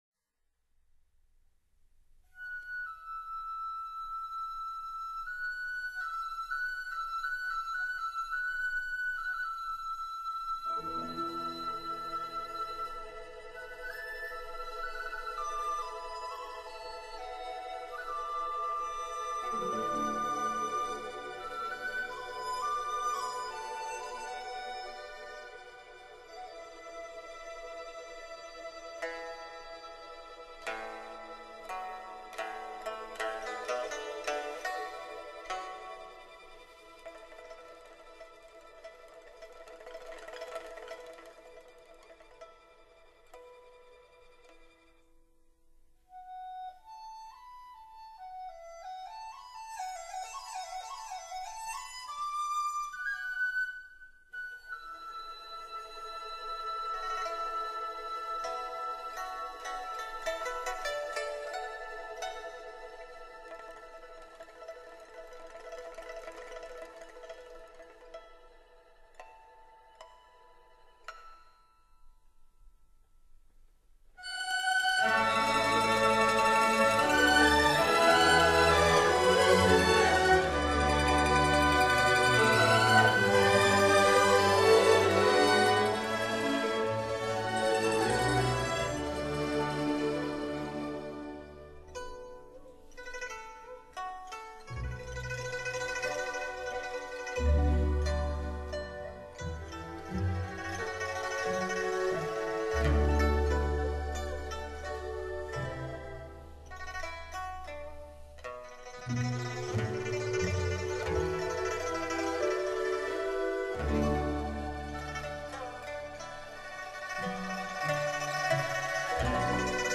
琵琶
琵琶音色丰满甜美,跟乐队合作纯熟,录音高度传神,是最具分量的琵琶与乐队专辑.